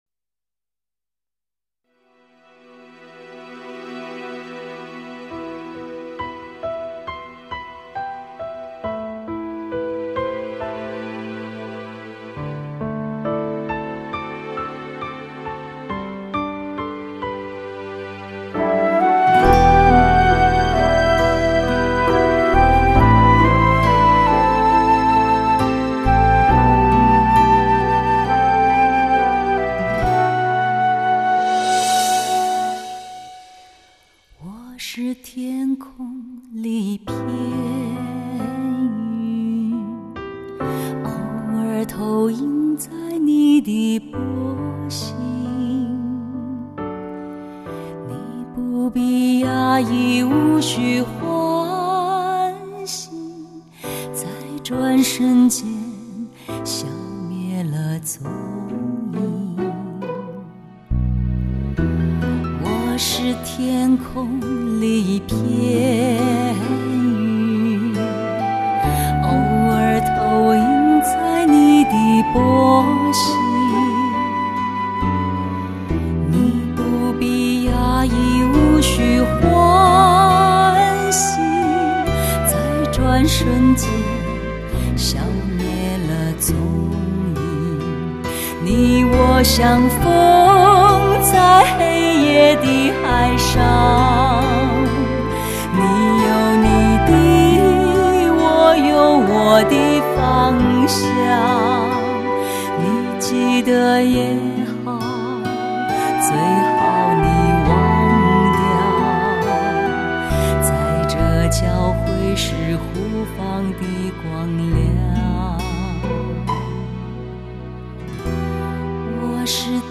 都是老歌 不错的翻唱 O(∩_∩)O谢谢